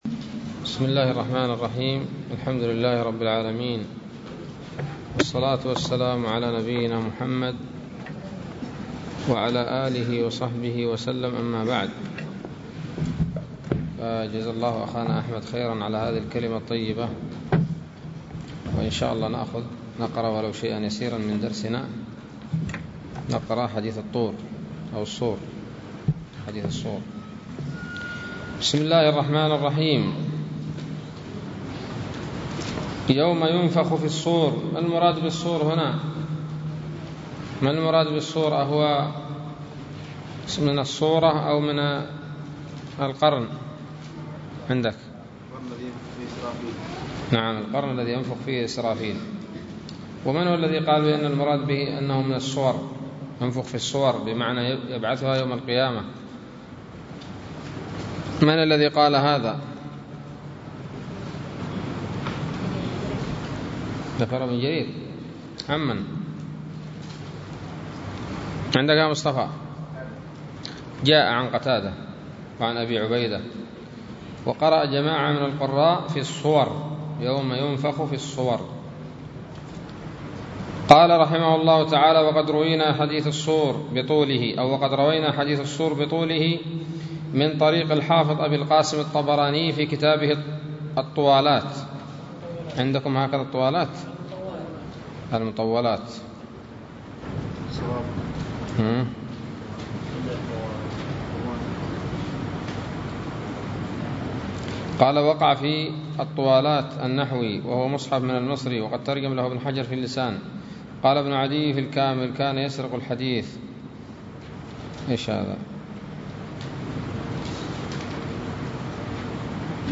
006 سورة الأنعام الدروس العلمية تفسير ابن كثير دروس التفسير
الدرس الخامس والعشرون من سورة الأنعام من تفسير ابن كثير رحمه الله تعالى